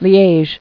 [Li·ège]